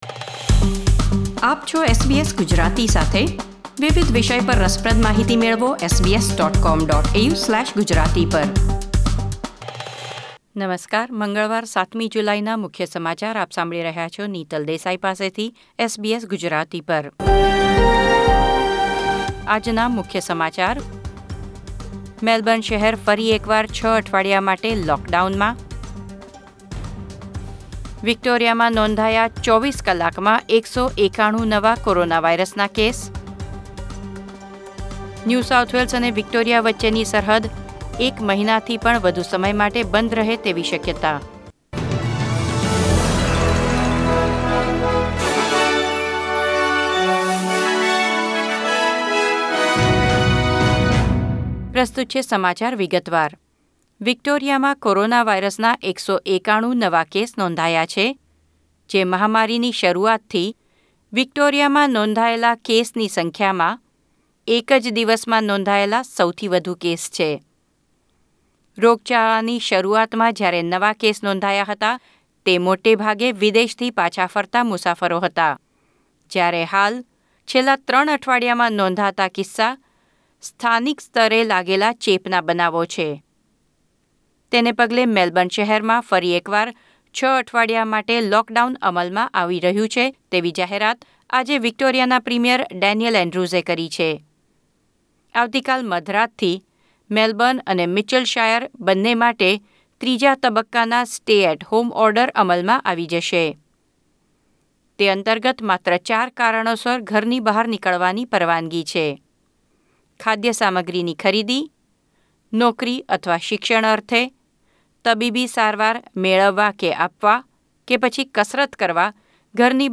SBS Gujarati News Bulletin 7 July 2020